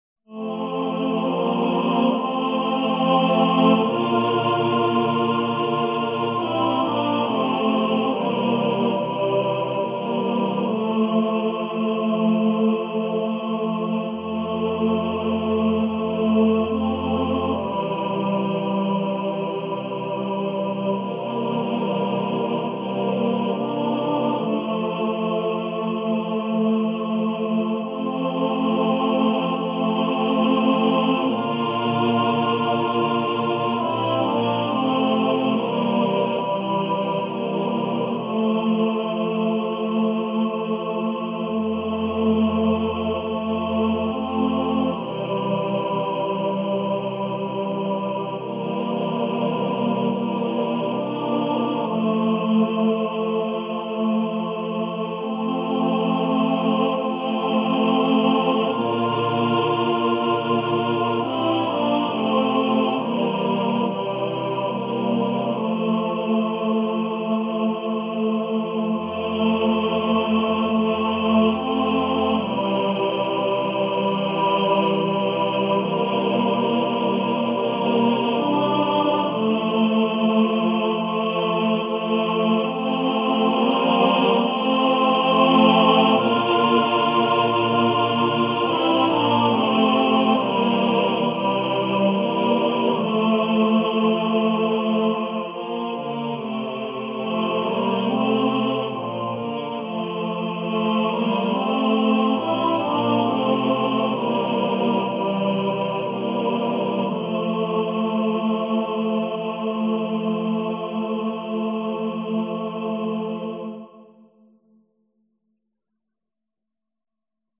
Leicht und wirkungsvoll, a cappella zu singen.